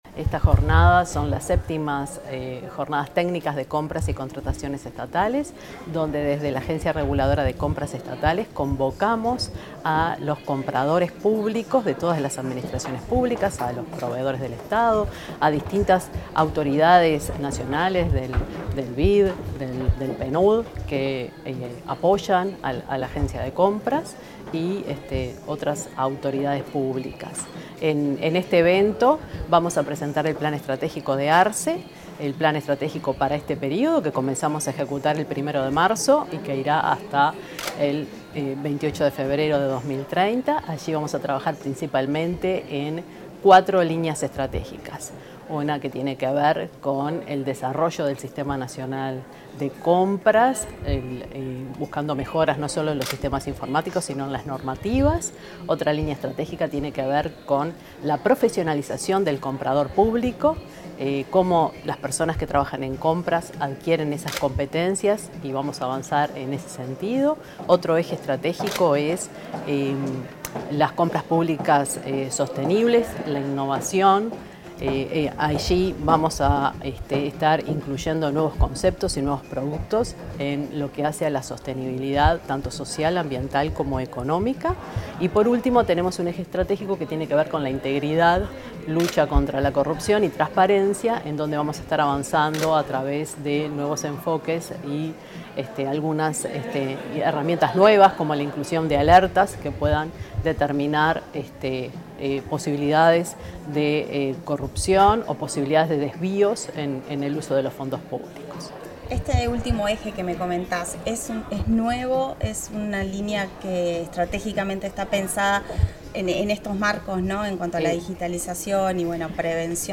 Declaraciones de la directora de ARCE, Isis Burguez